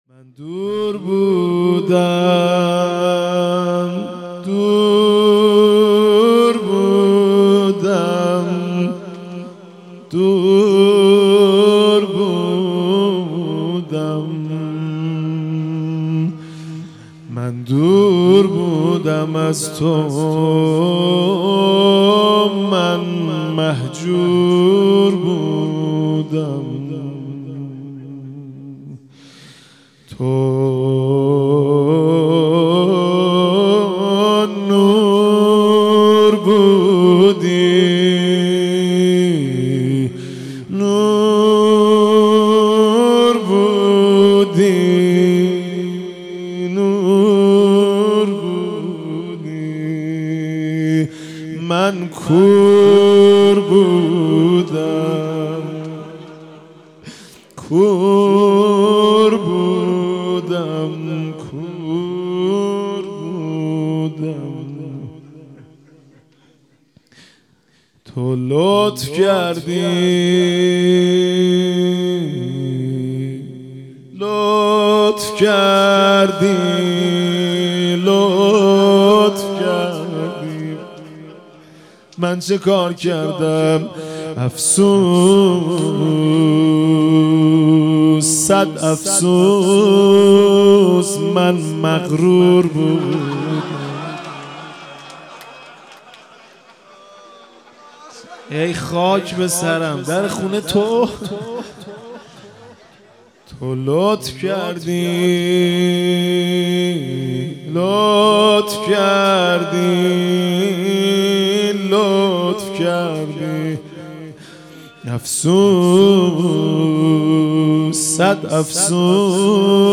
به مناسبت حلول ماه مبارک رمضان، مناجات زیبا و دلنشین حاج مهدی رسولی در شب ششم ماه رمضان 1401 را به مدت 4 دقیقه با روزه داران و میهمانان سفره الهی به اشتراک می گذاریم.